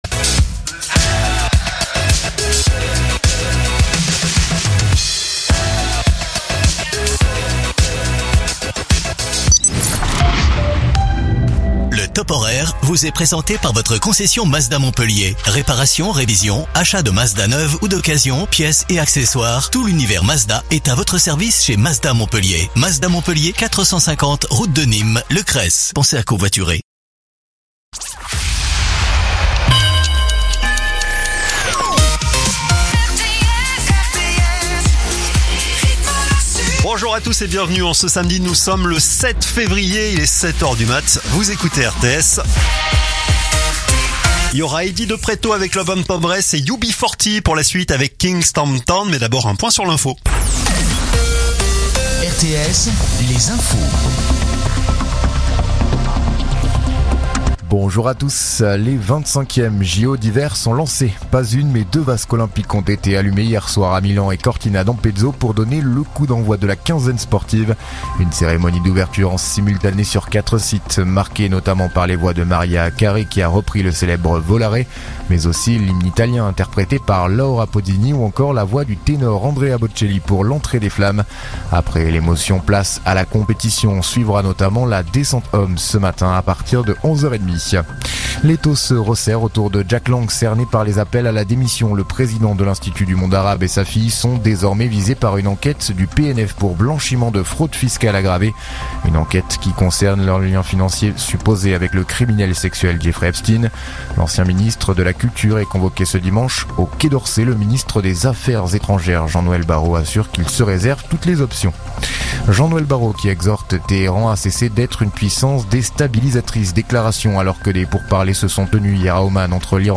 info_mtp_sete_beziers_652.mp3